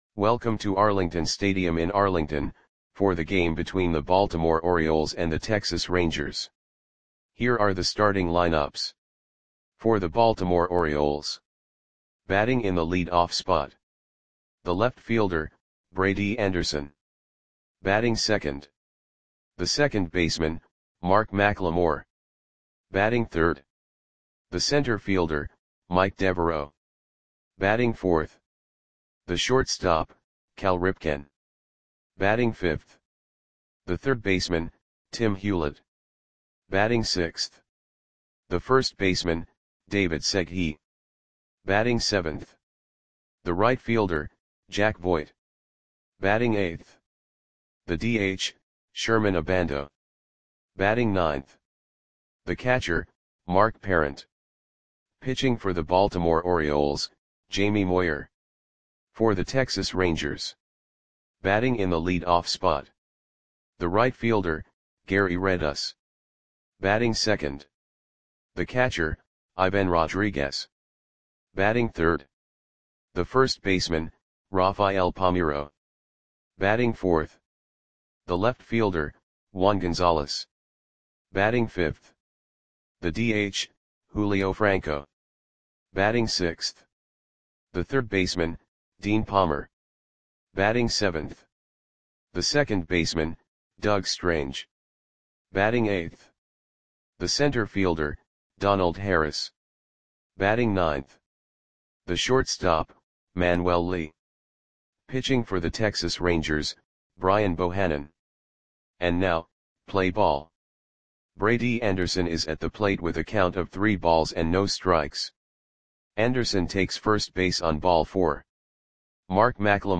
Lineups for the Texas Rangers versus Baltimore Orioles baseball game on August 29, 1993 at Arlington Stadium (Arlington, TX).
Click the button below to listen to the audio play-by-play.